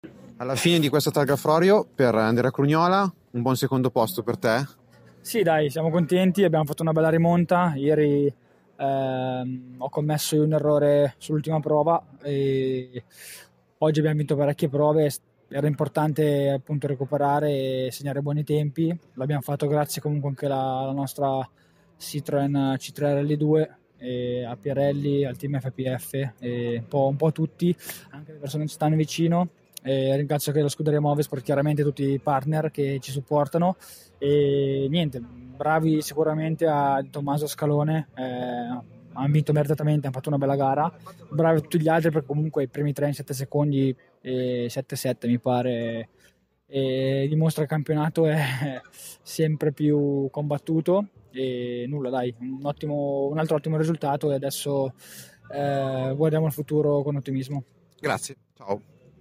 Interviste 106° Targa Florio
Interviste finali